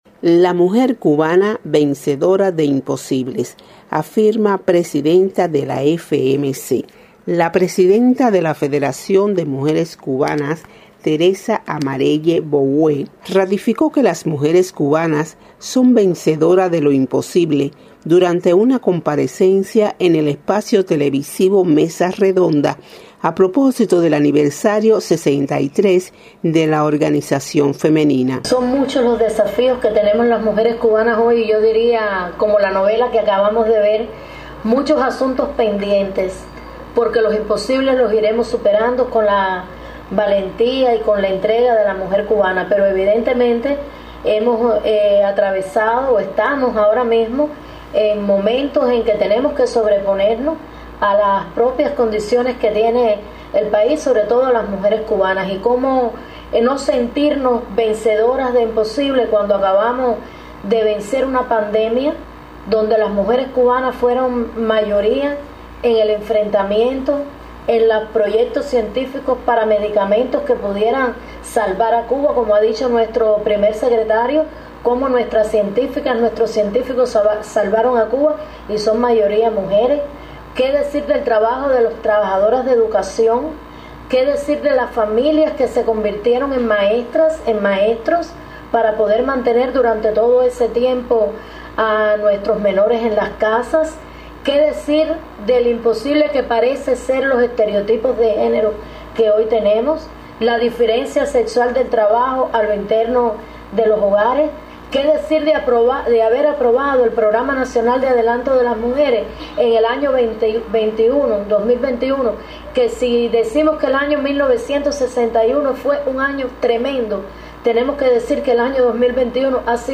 La presidenta de la Federación de Mujeres Cubanas, Teresa Amarelle Boué, ratificó que las mujeres cubanas son vencedoras de lo imposible durante una comparecencia en el espacio televisivo Mesa Redonda, a propósito del aniversario 63 de la organización femenina.